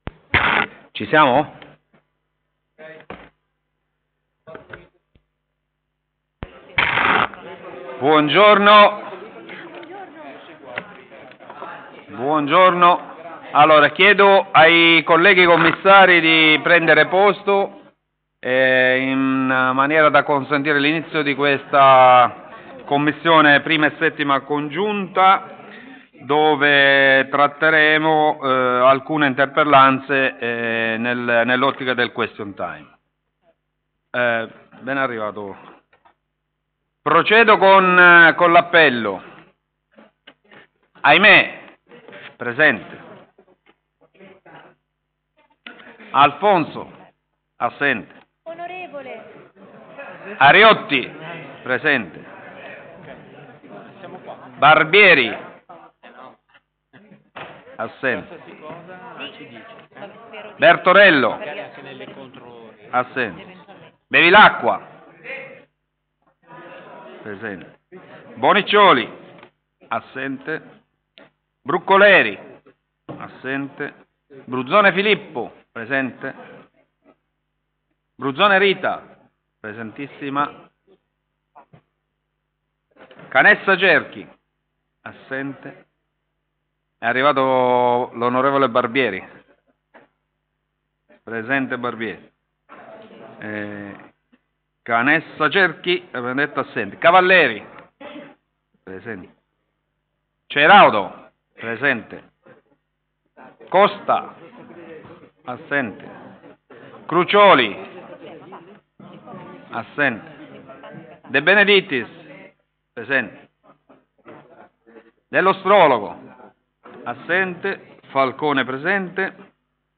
Question time
commissione_consiliare_i_vii_di_venerdi_17_gennaio_ore1130.mp3